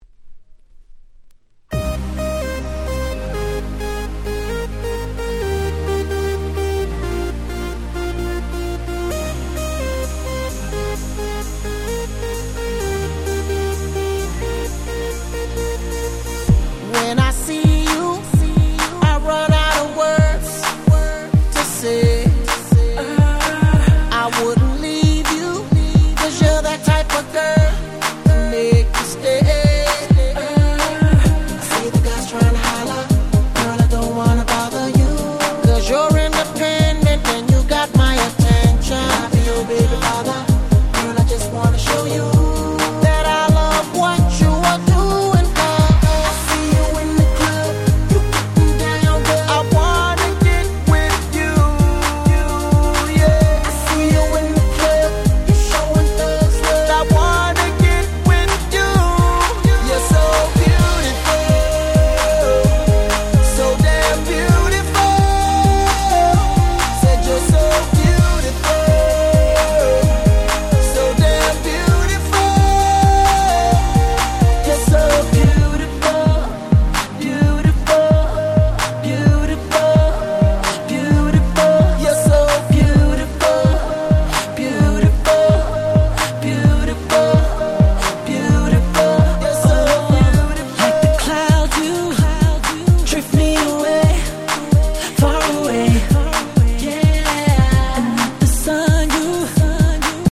09' Super Hit R&B !!
当時の流行りな感じのEDM調なイントロから始まるアップナンバー！！